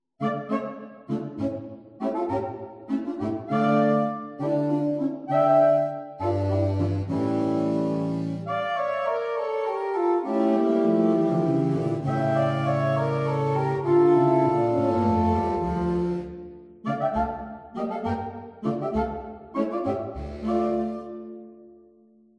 Performance excerpts
Saxophone Quartet